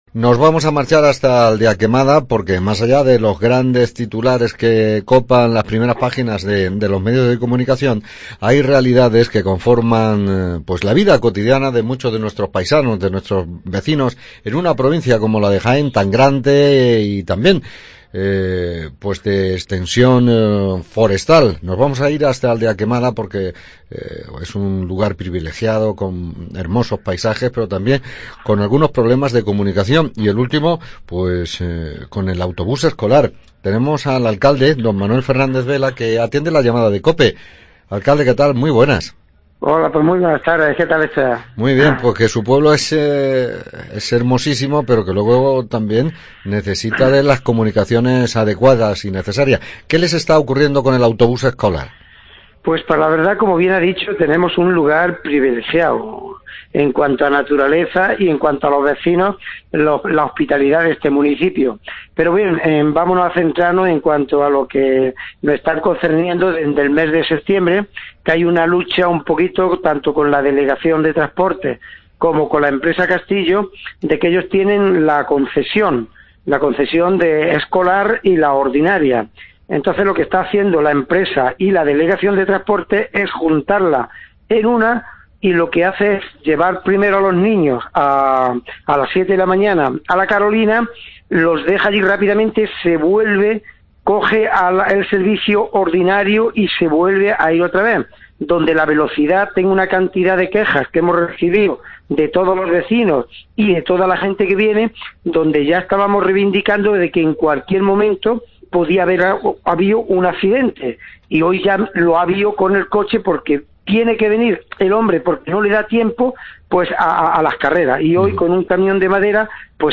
ENTREVISTA ALCALDE DE ALDEAQUEMADA